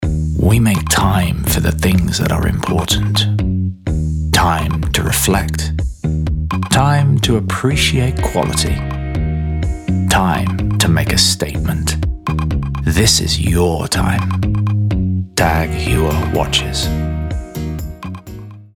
Voice Reel
Assured, Rich, Cool